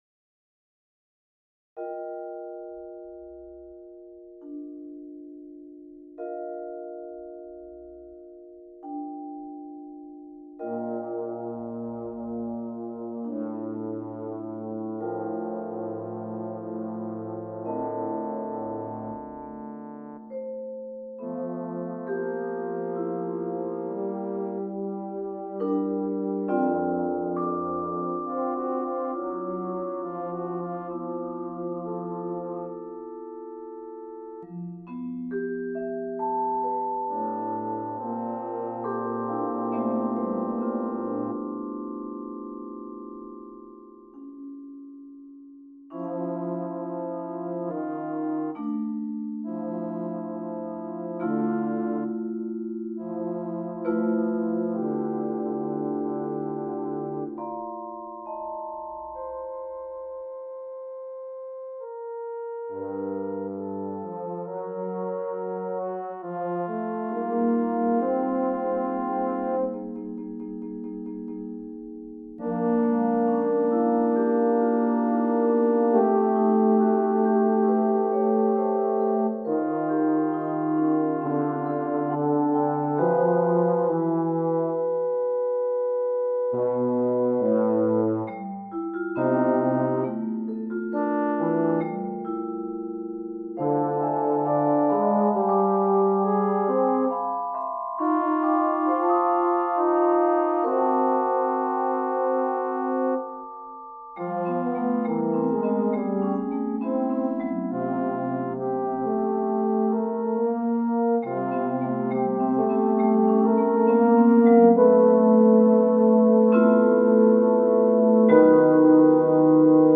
Trio: French Horn, Euphonium, Vibraphone
French Horn
Euphonium
Vibraphone